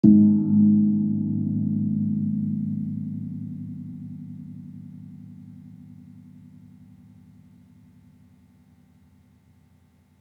Gong-G#2-f.wav